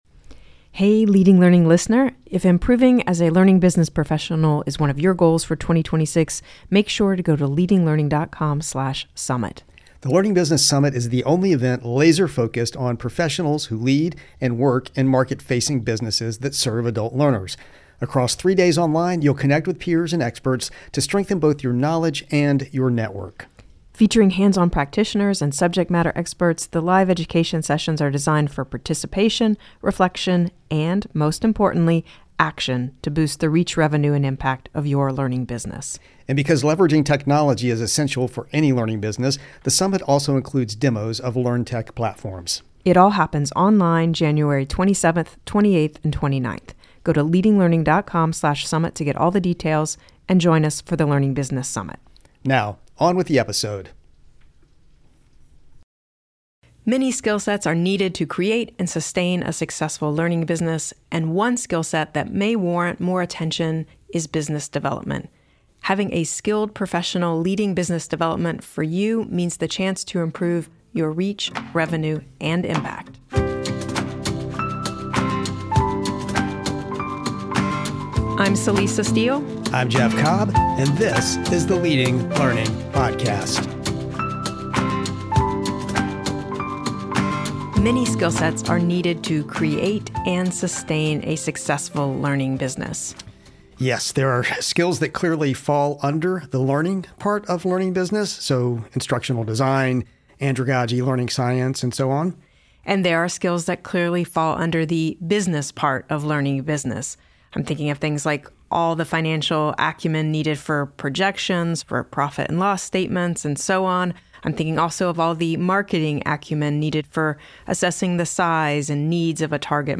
The Leading Learning Podcast co-hosts look at what a business development professional should be able to do for a learning business.